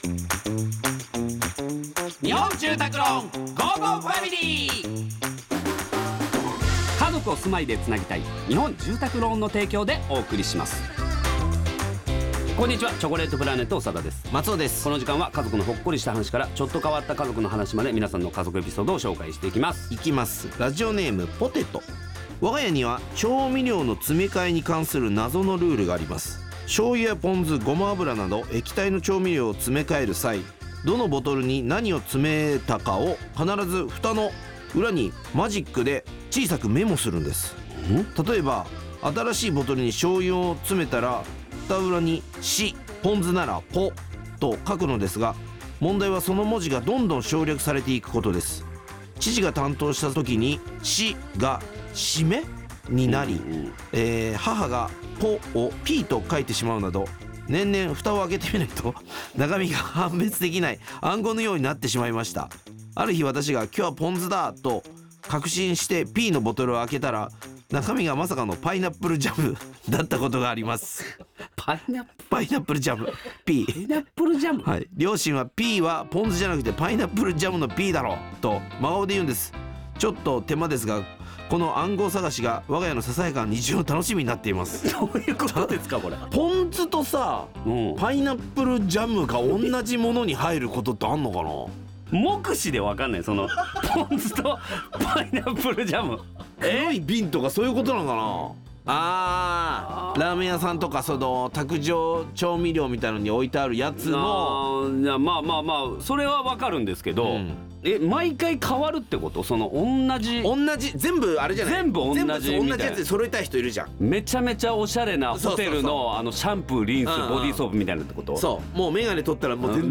リスナーさんのお宅のルールと 聞き馴染みのない調味料にチョコプラ大爆笑!!